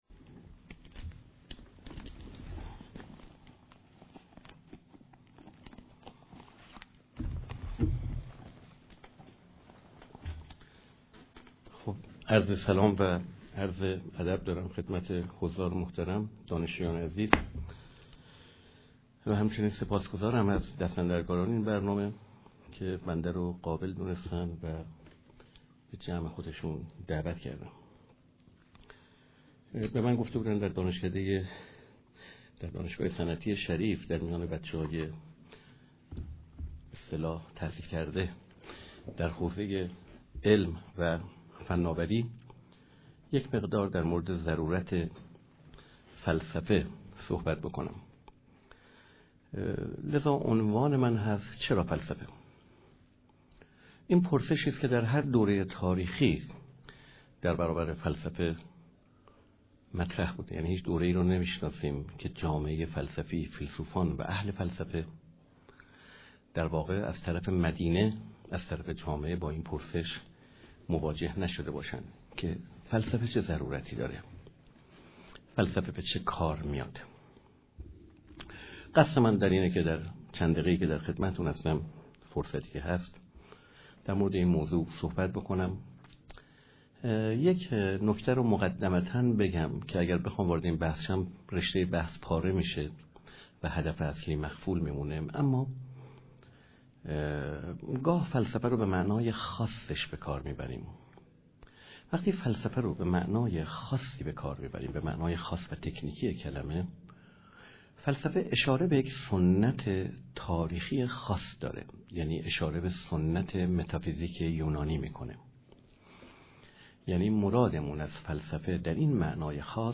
در همایش روز جهانی فلسفه است که در آذر ماه ۹۳ در دانشگاه صنعتیشریف برگزار شد.